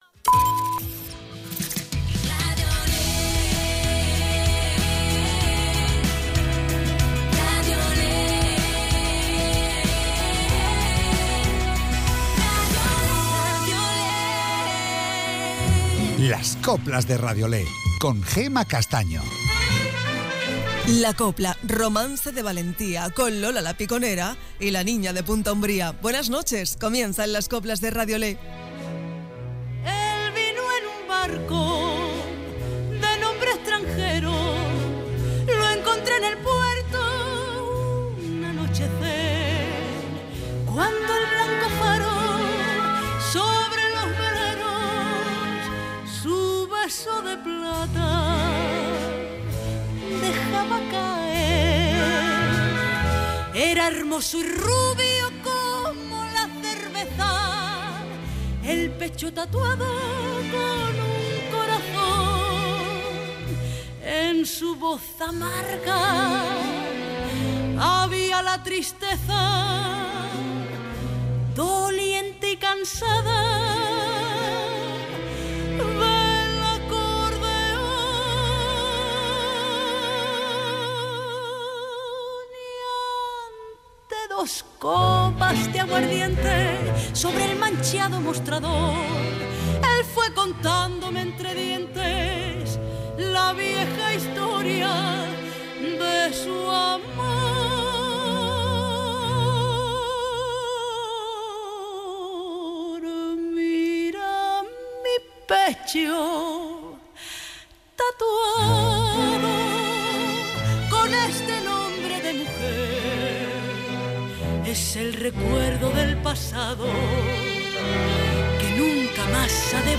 Las mejoras coplas de hoy y de siempre